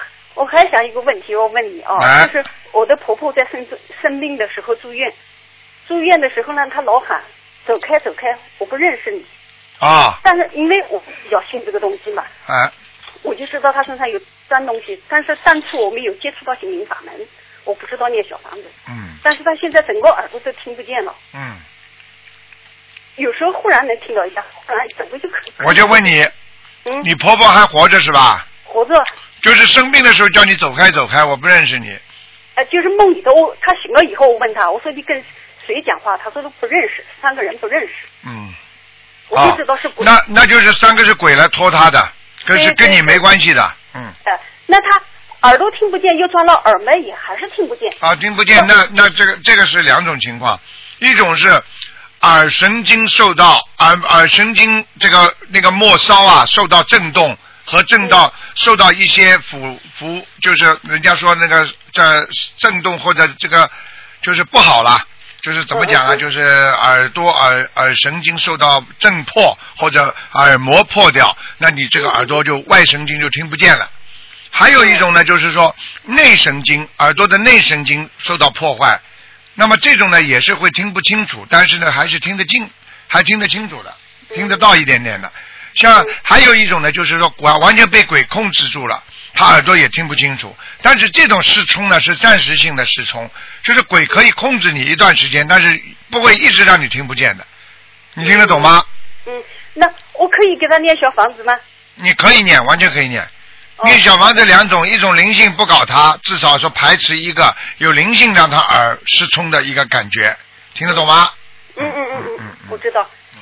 Tanya Jawab
Pendengar wanita: Ketika ibu mertua sakit dirawat di rumah sakit selalu berteriak “pergi pergi, saya tidak mengenalmu…”